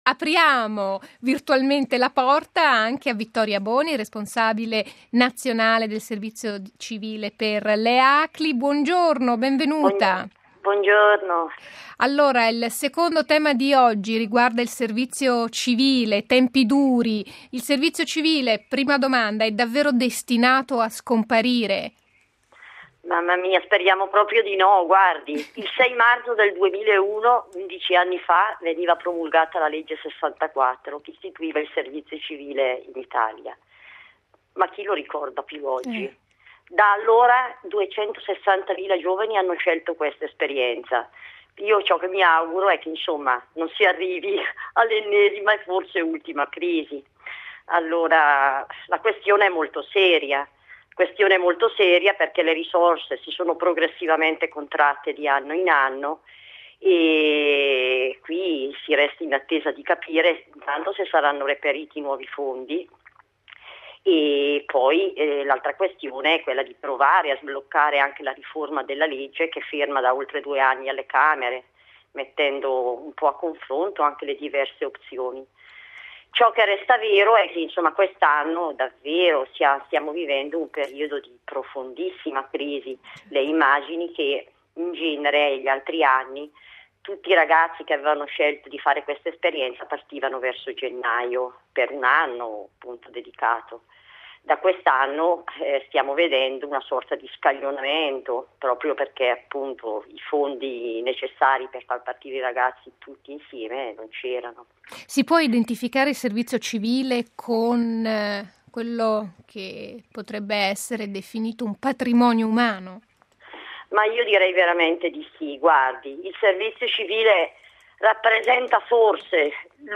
Stiamo parlando del servizio civile, un istituto voluto dallo stesso Stato che ha permesso a centinaia di migliaia di ragazzi di fare un'esperienza di solidarietà, impegno e servizio. Ai nostri microfoni, il commento di